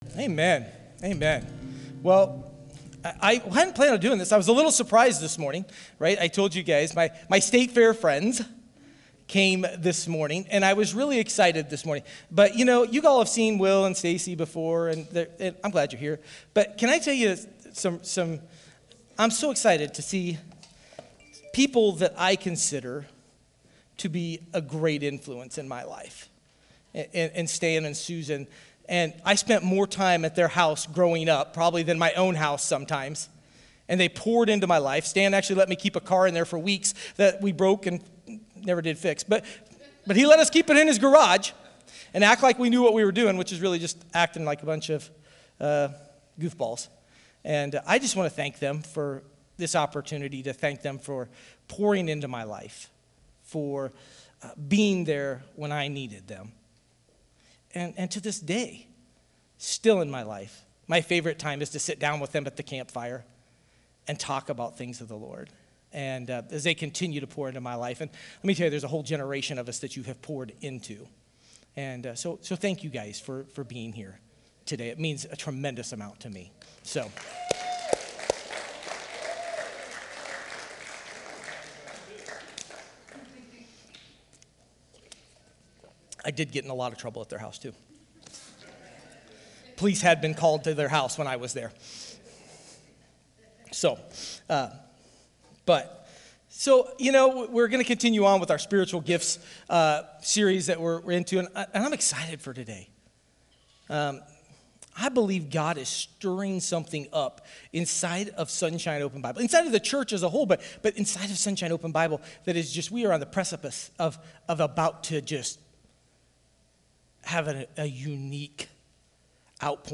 Sermons | Sunshine Open Bible Church